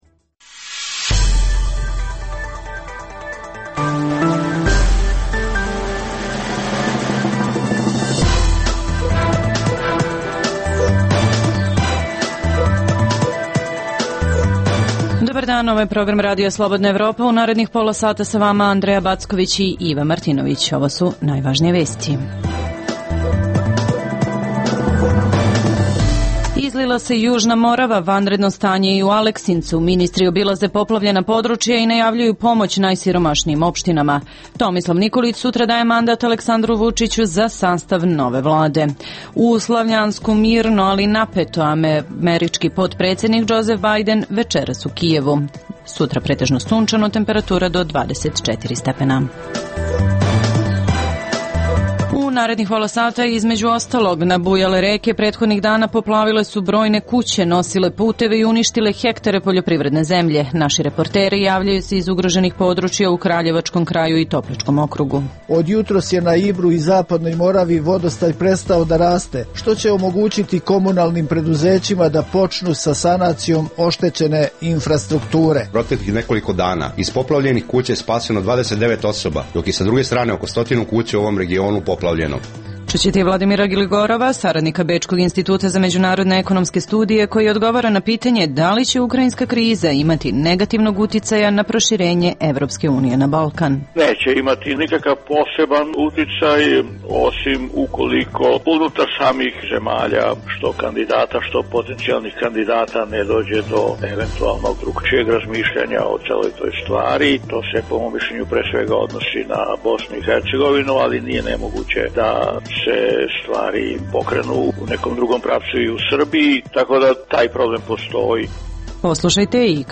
Naši reporteri javljaju se iz ugroženih područja.